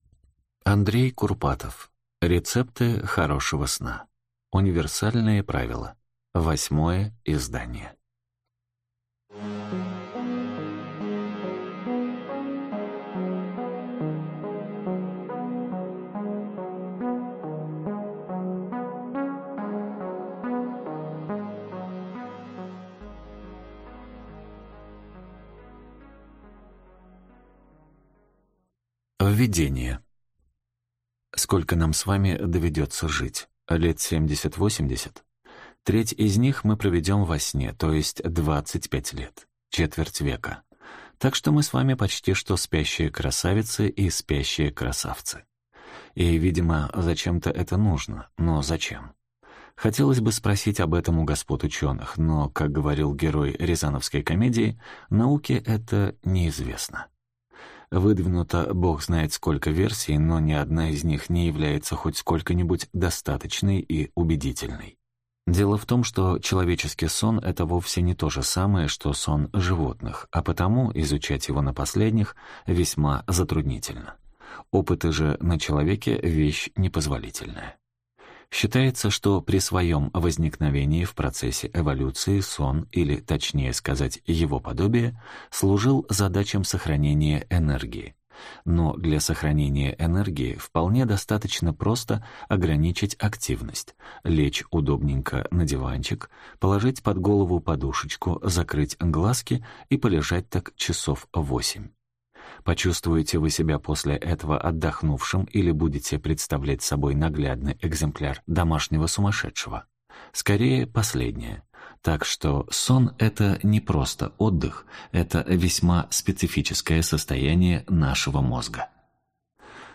Аудиокнига Рецепты хорошего сна | Библиотека аудиокниг